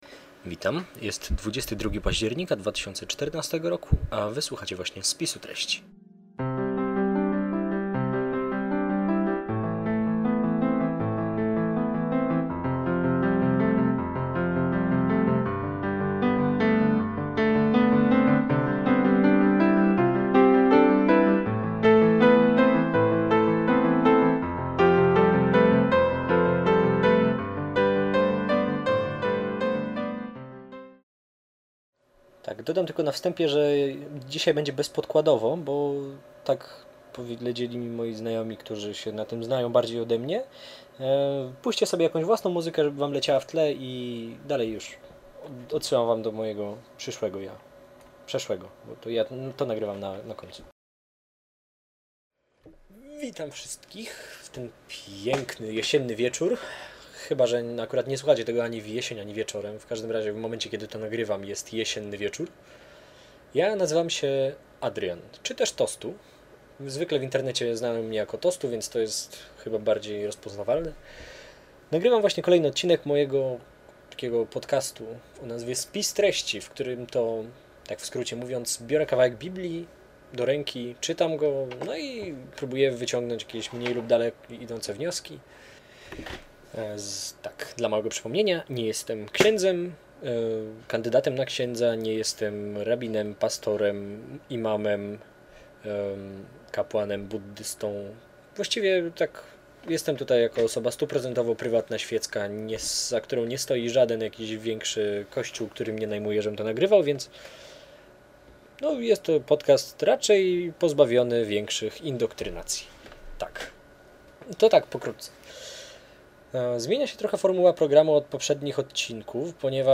Swobodne gadanie o Bogu prostym, nieteologicznym językiem.